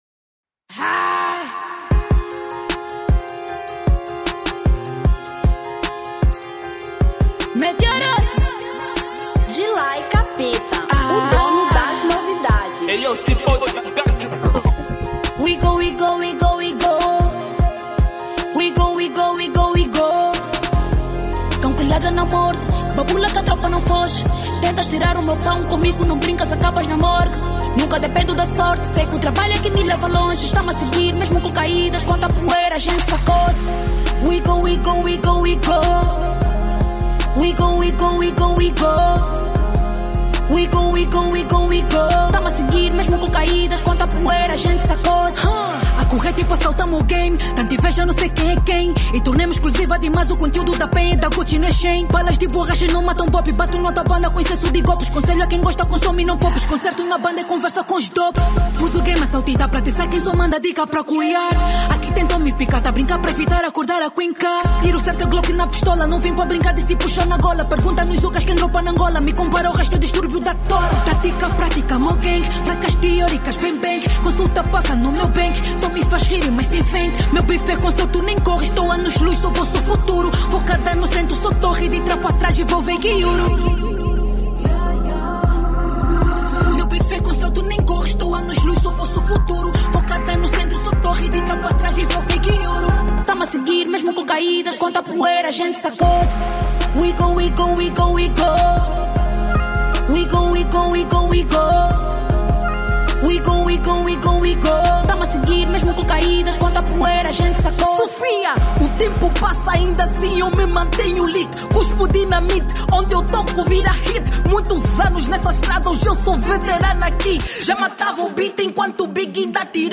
Trap 2025